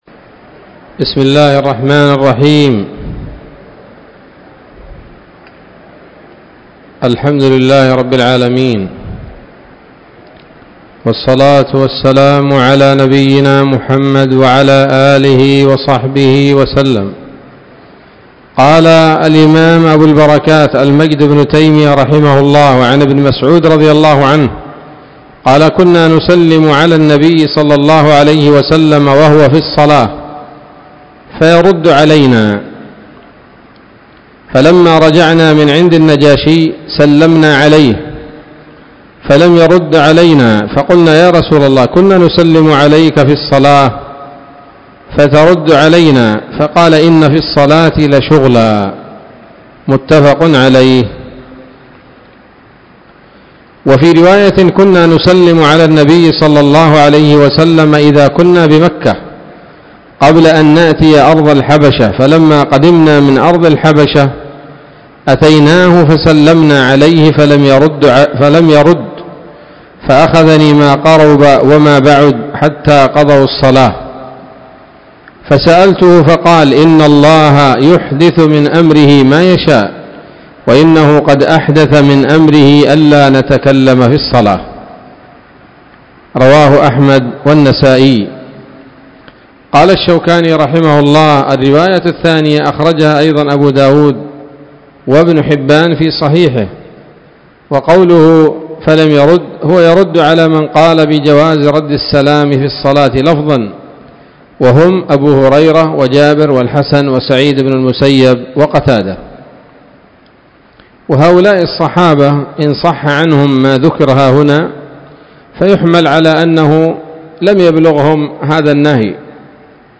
الدرس الرابع من أبواب ما يبطل الصلاة وما يكره ويباح فيها من نيل الأوطار